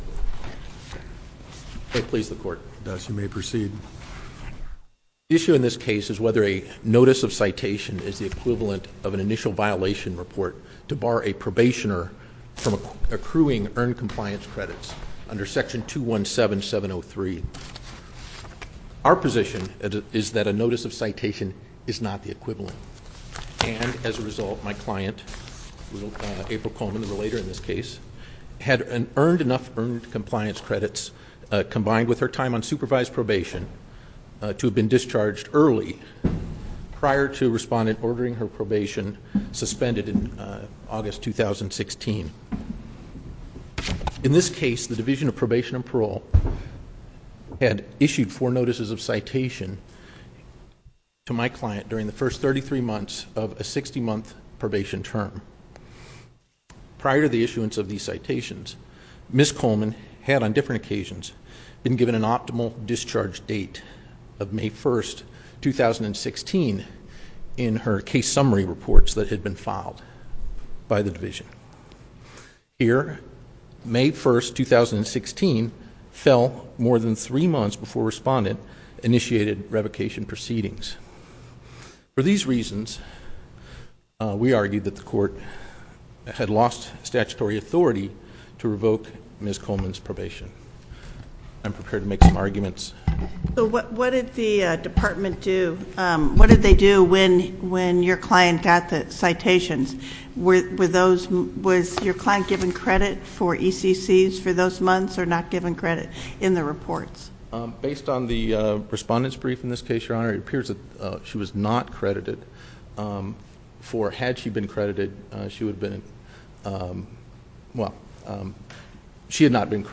link to MP3 audio file of oral arguments in SC97198